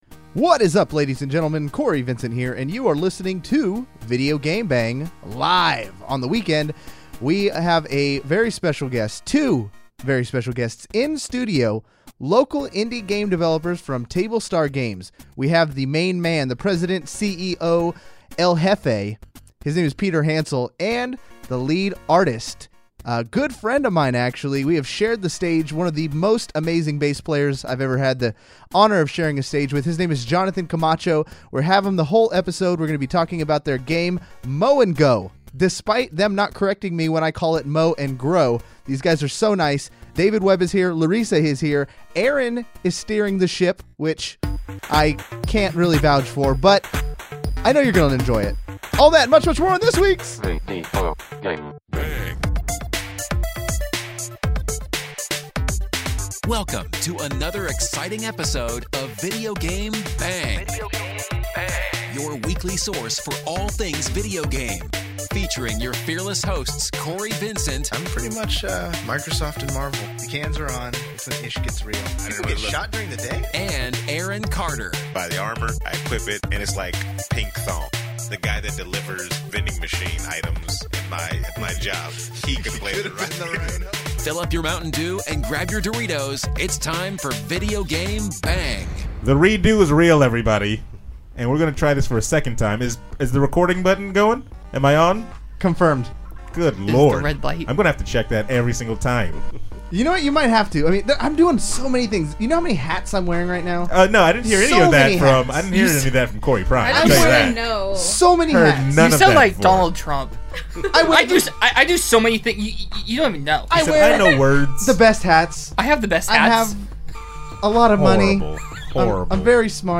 The Videogame BANG! team welcomes more Sacramento Indie Game Devs to the studio to promote their latest games and talk about the Sacramento Indie Arcade!